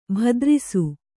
♪ bhadrisu